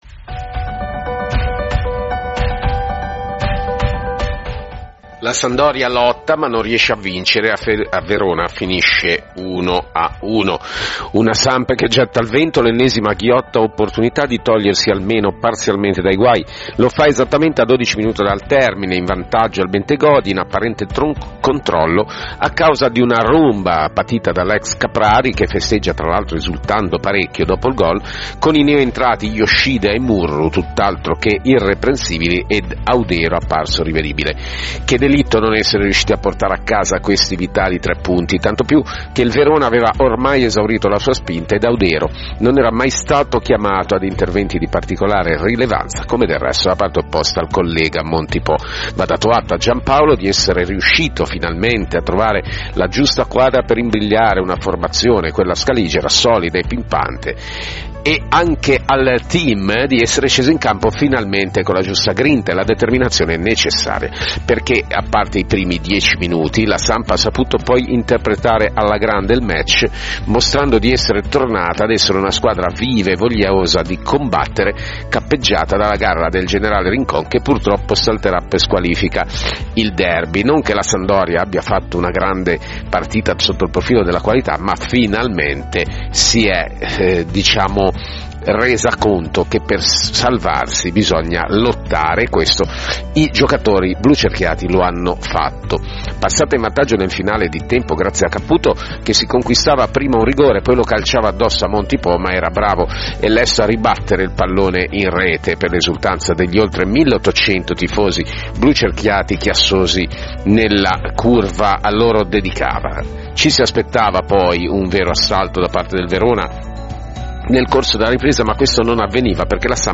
Il commento audio del Giornalista
dallo stadio “Bentegodi”